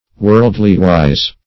World"ly-wise`